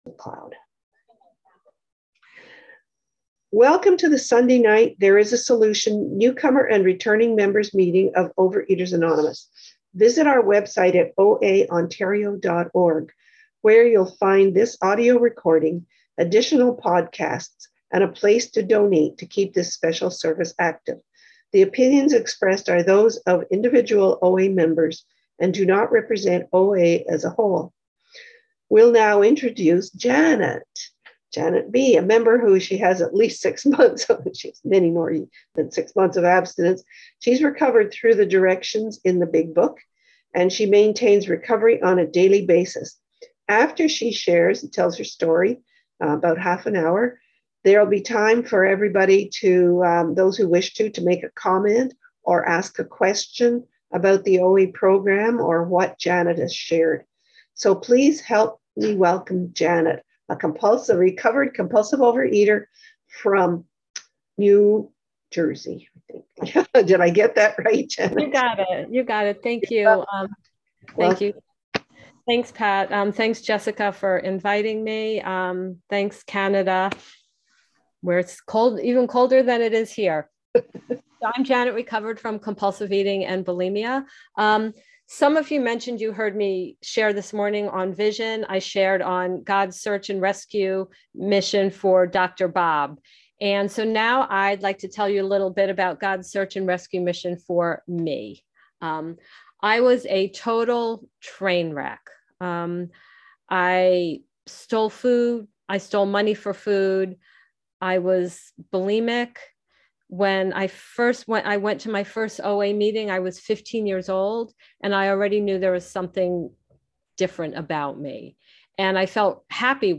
OA Newcomer Meeting
Speaker Files